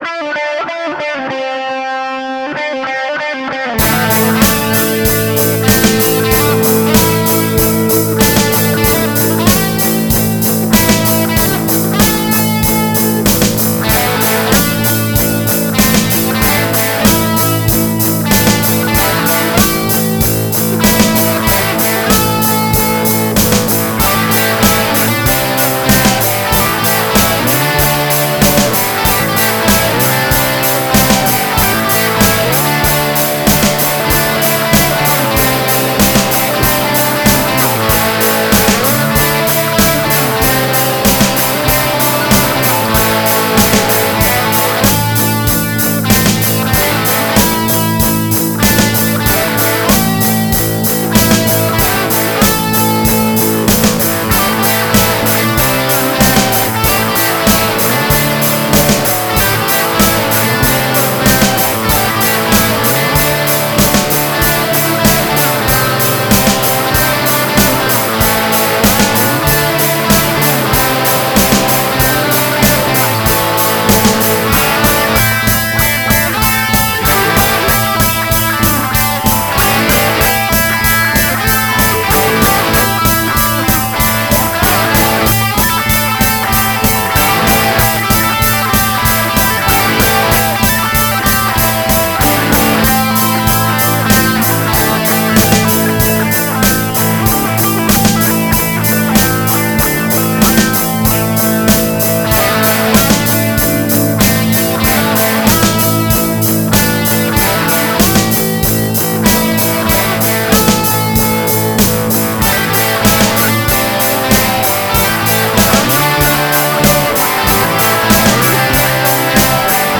Friday is Guitar Day
Now with some poorly-played keyboards as well, because I am growing as an artist.
I claim copyright anyway, so there; 3. No, I cannot do anything about the general quality of the mix, as I am incompetent.
gee-tar